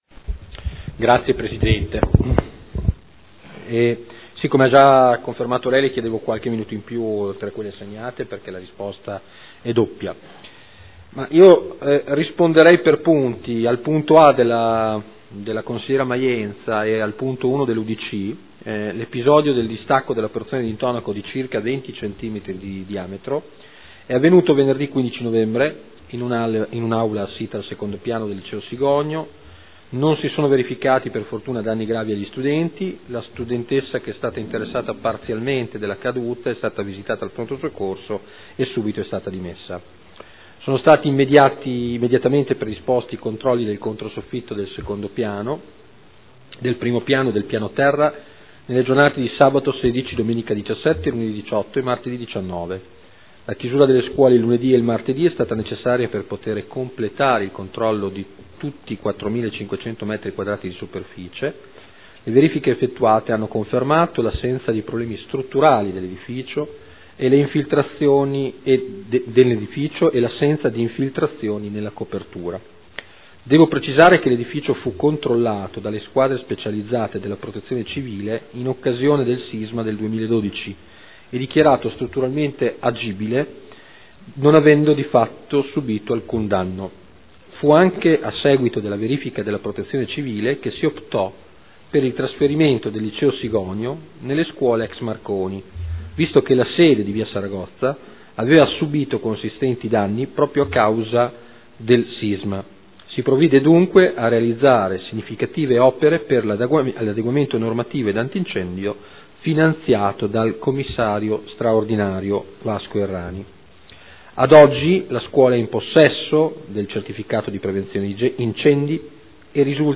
Marino Antonino — Sito Audio Consiglio Comunale